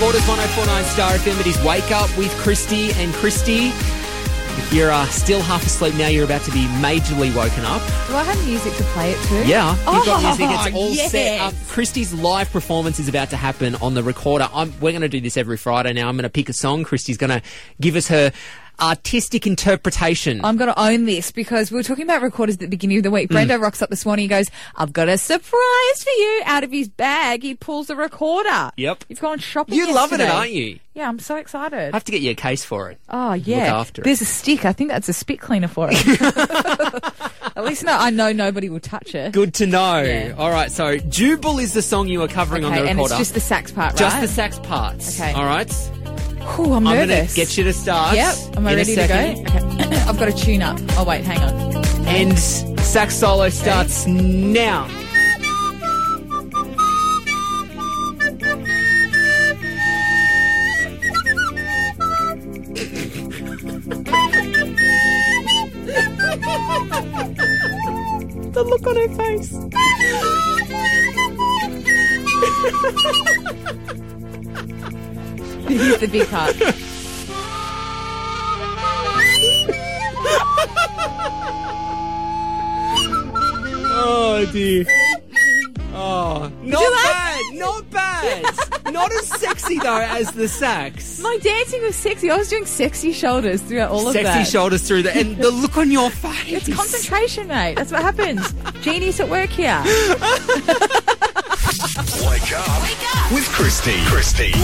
My recorder solo